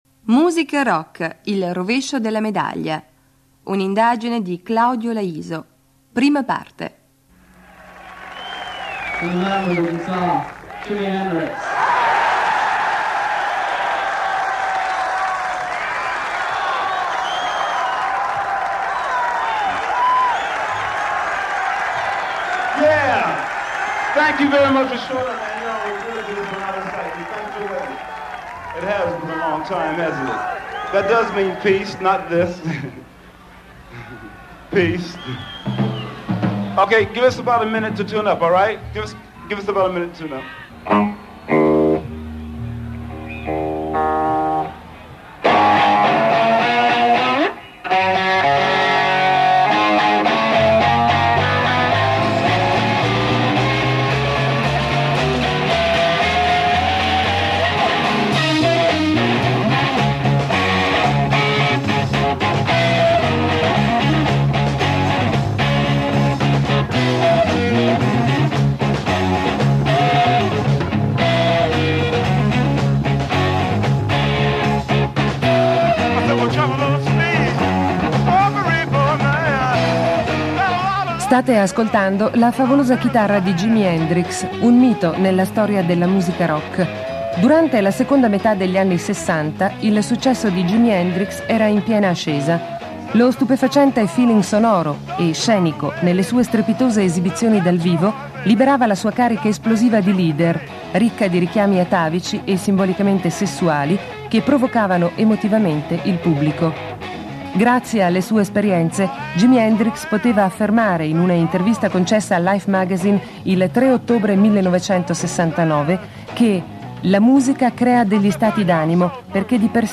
audio conferenza musica rock: il rovescio della medaglia
Si tratta di un'indagine contenente due programmi culturali con interviste e testi redazionali commentati con musiche di sonorizzazione di sottofondo.